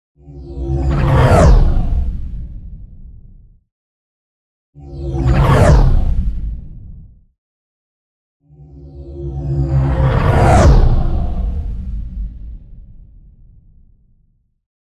Download Science Fiction sound effect for free.
Science Fiction